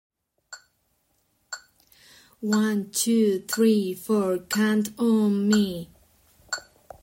one_two_three_four_count_on_me2.mp3